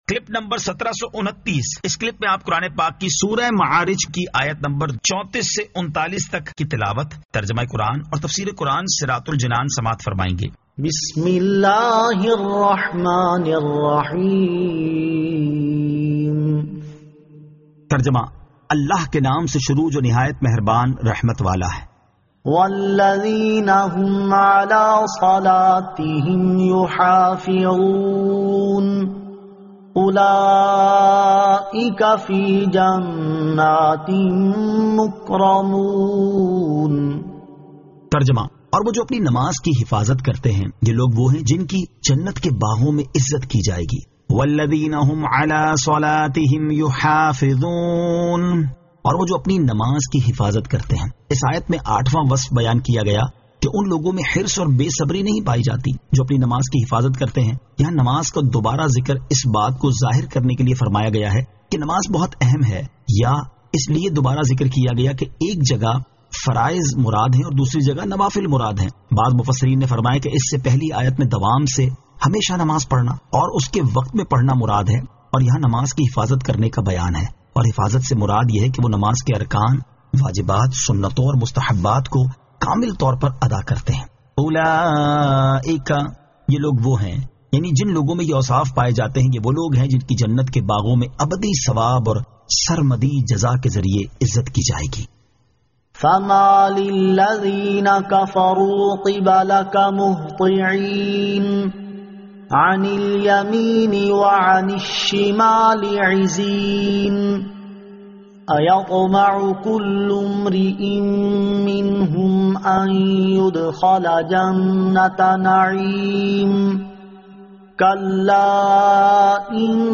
Surah Al-Ma'arij 34 To 39 Tilawat , Tarjama , Tafseer